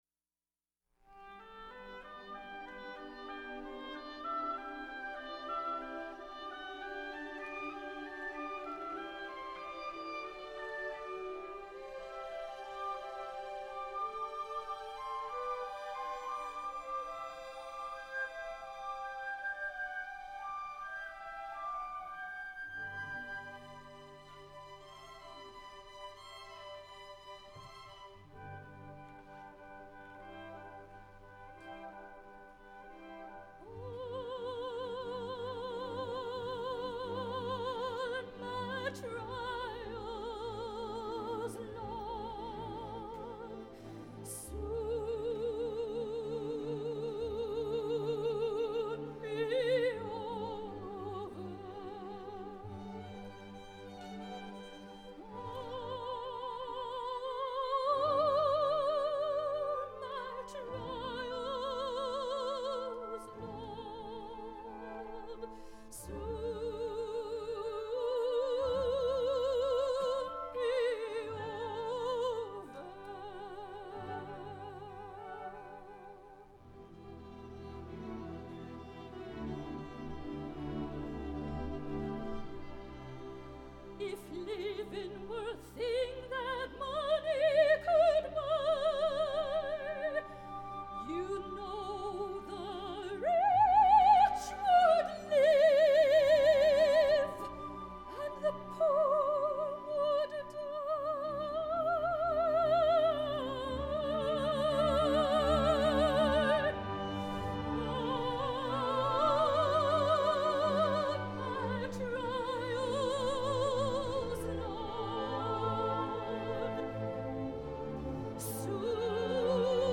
for Soprano and Orchestra (2010)